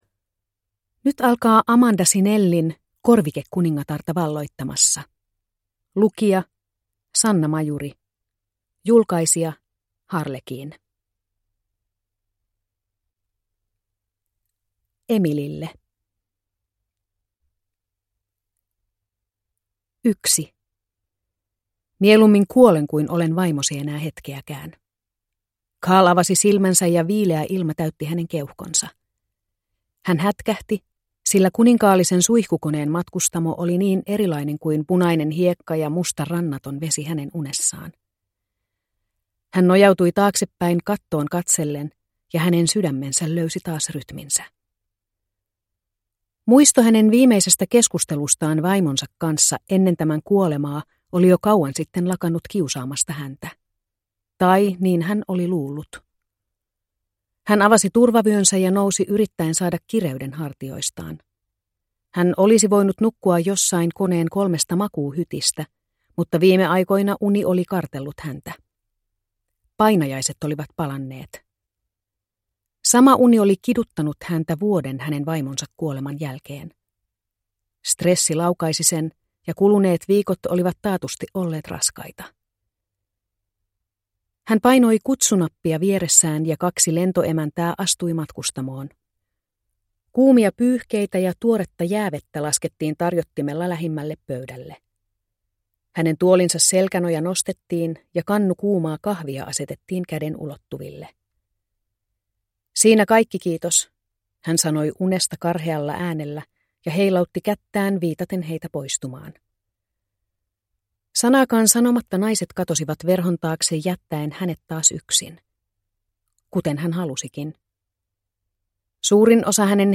Korvikekuningatarta valloittamassa (ljudbok) av Amanda Cinelli